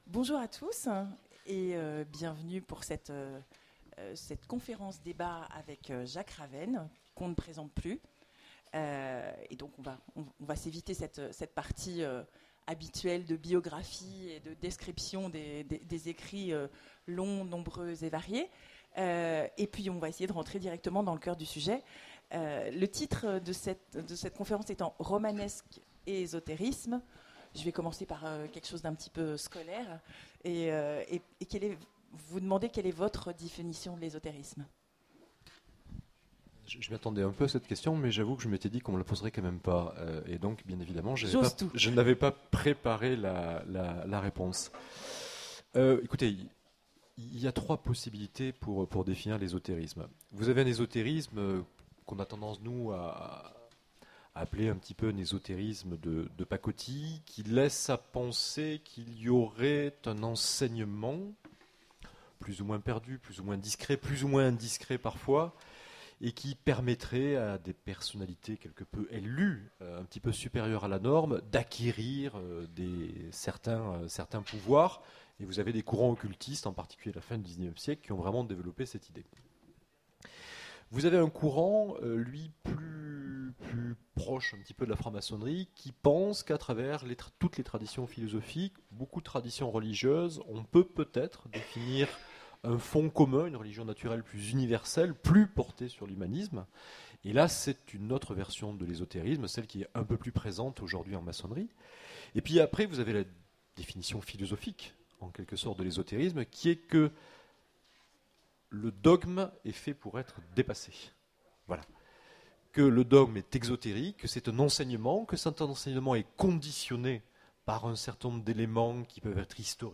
Imaginales 2015 : Conférence Romanesque et ésotérisme